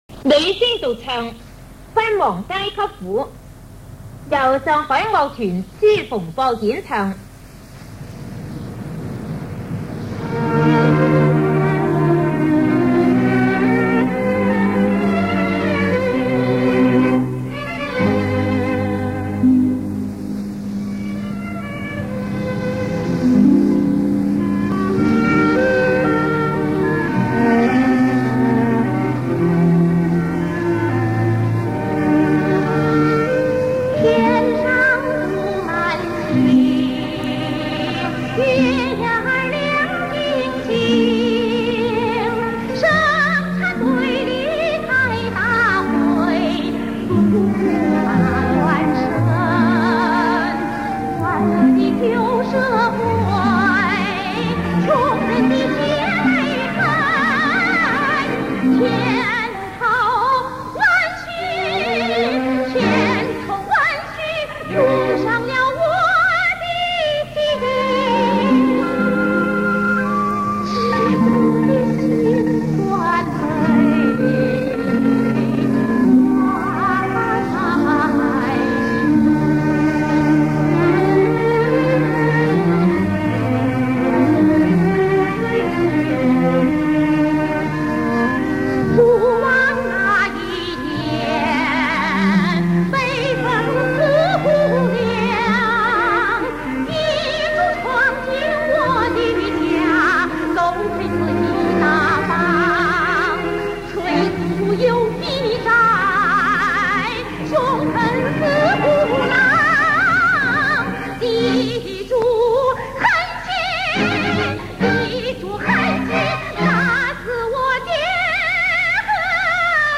当年广播电台播放该节目的时候，他用家庭收录机录音了下来。
音质不够理想，但作为宝贵的资料来听，还是有价值的。